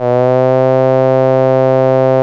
VOICE C2 S.wav